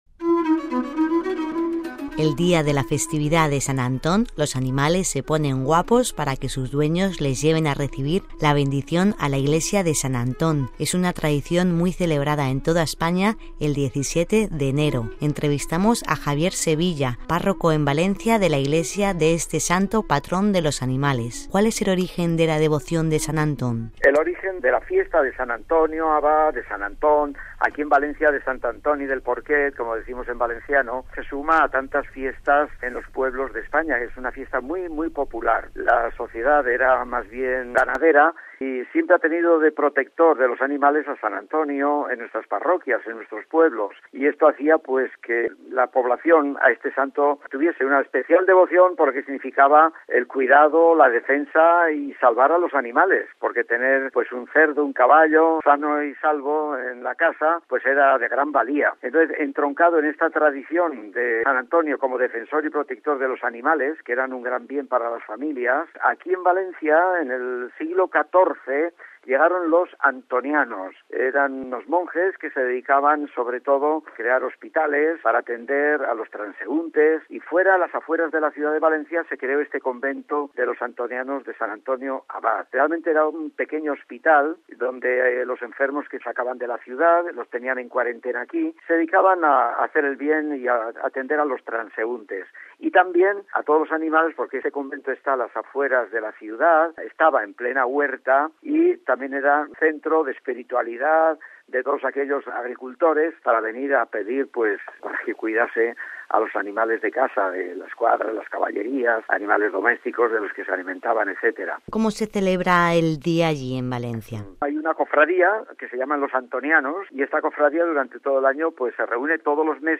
También en España la festividad de San Antón se celebra de una forma muy especial, bendiciendo a los animales en las iglesias. Nos lo explica